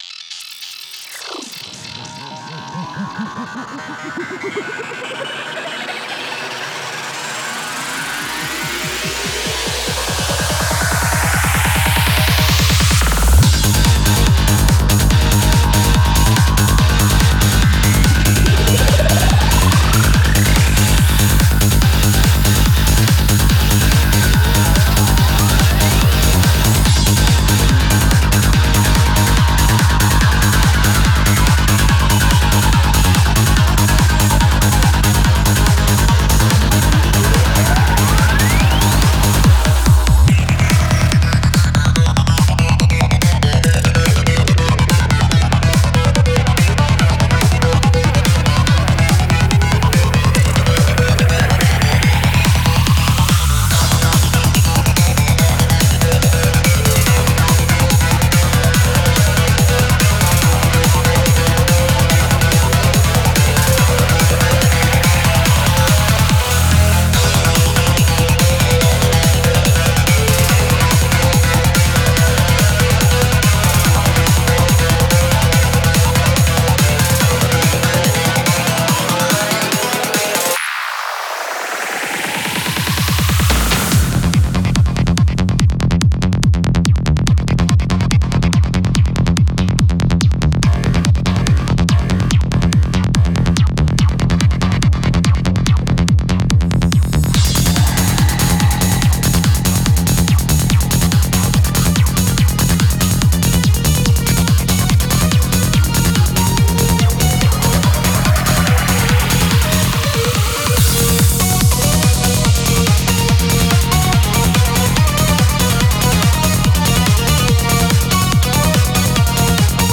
Bpm: 138-145
Genre: Psytrance